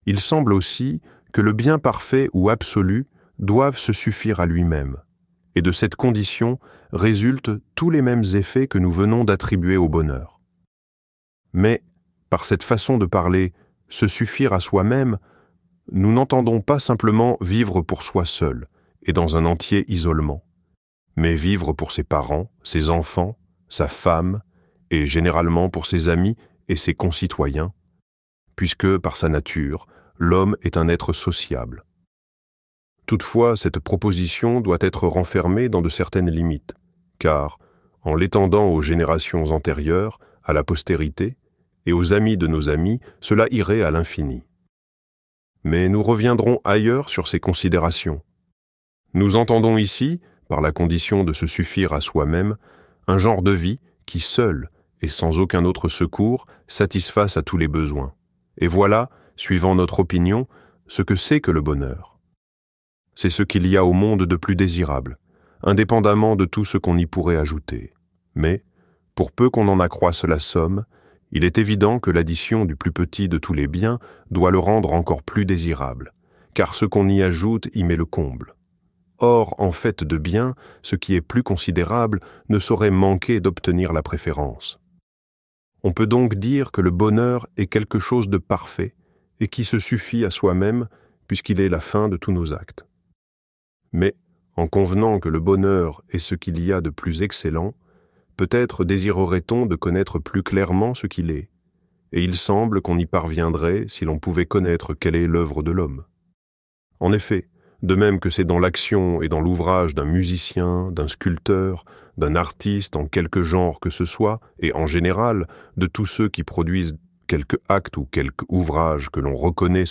Éthique à Nicomaque - Livre Audio